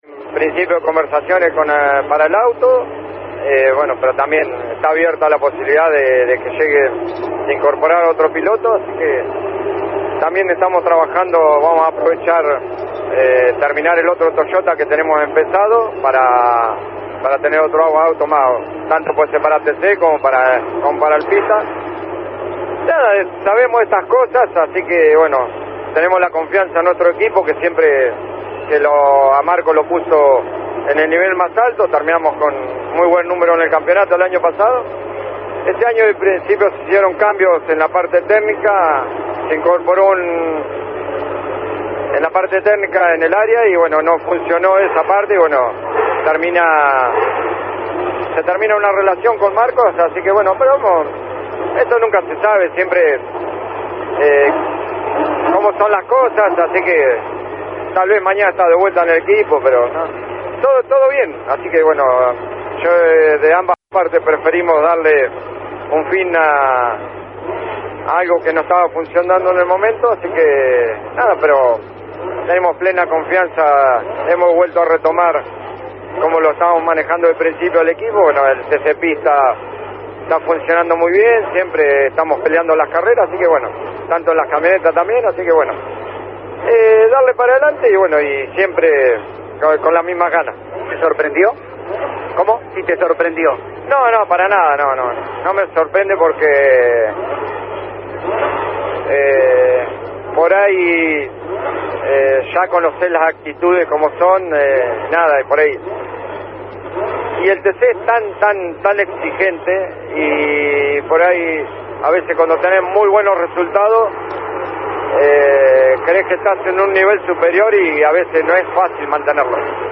Insistió en Campeones por Radio Continental que «tenemos ganas de emprender un nuevo proyecto para estar en lo más alto«.